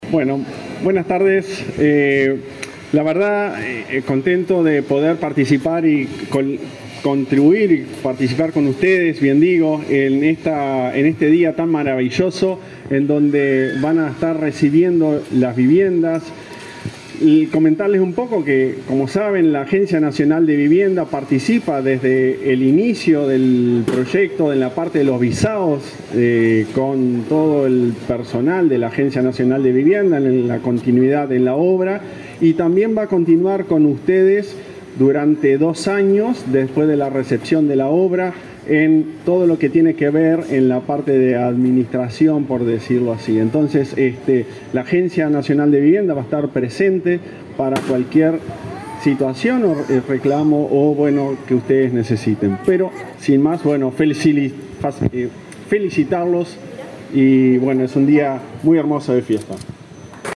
Palabras del presidente de la Agencia Nacional de Vivienda
El titular de la Agencia Nacional de Vivienda, Klaus Mill von Metzen, participó este viernes 8, de la entrega de soluciones habitacionales en